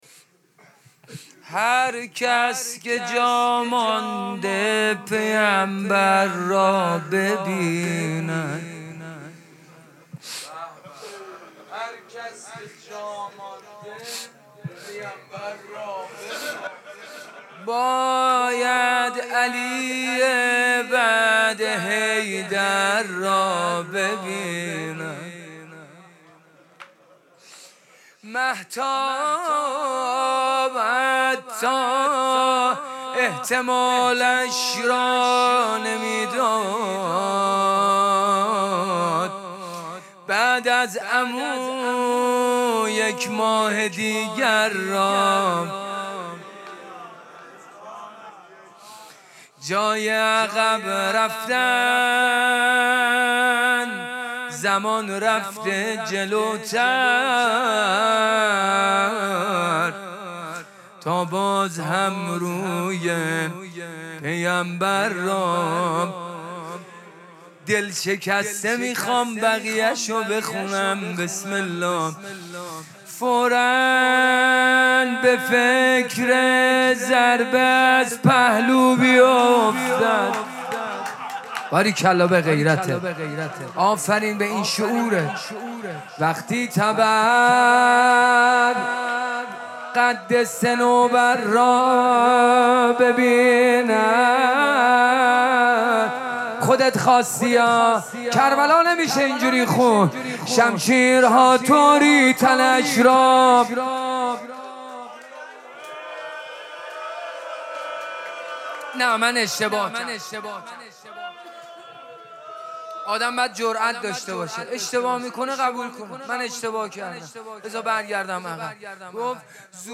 مراسم مناجات شب بیستم ماه مبارک رمضان
حسینیه ریحانه الحسین سلام الله علیها
روضه